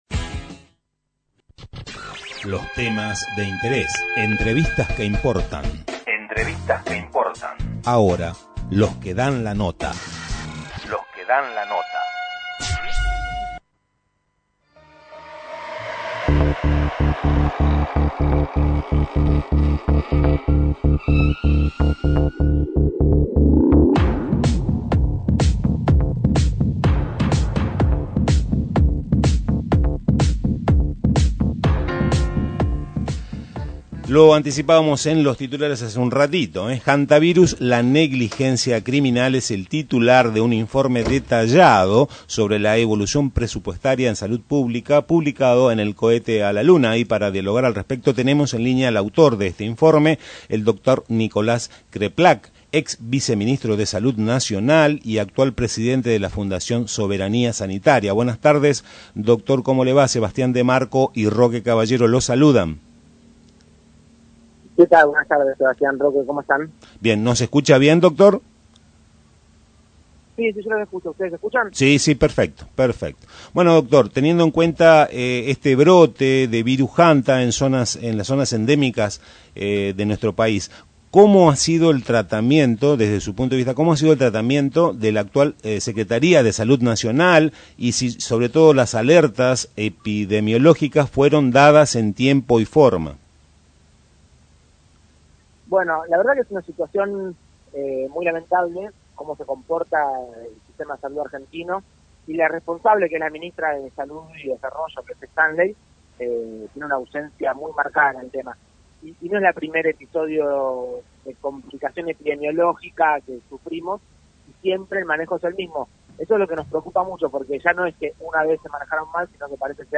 De esta manera se expresaba el Dr.Nicolás Kreplak ex viceministro de salud nacional y titular de la Fundación Soberanía Sanitaria, dialogó en Tren Urbano de verano sobre la situación sanitaria en el sur del país con el brote de Hantavirus, abordó sobre las consecuencias del recorte presupuestario en determinadas áreas de prevención y promoción de la salud pública y las consecuencias.